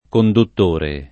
kondutt1re] s. m. e agg.; f. -trice — in senso di «persona che conduce», nell’uso del ’200 e ’300 anche conducitore [kondu©it1re] (f. -trice): conducitrice Fu de’ miei passi [kondu©itre f2 dde mLHi p#SSi] (Dante) — solo conduttore in ogni altro senso: conduttore elettrico; filo conduttore; anche di persona, per «affittuario; gestore; corridore automobilista; presentatore televisivo» (ecc.)